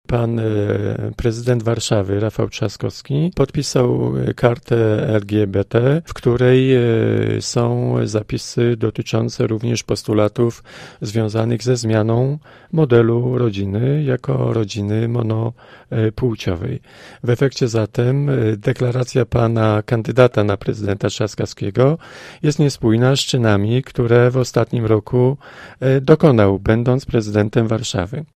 Dalsza wizja prezydentury Andrzeja Dudy w komentarzu posła Prawa i Sprawiedliwości.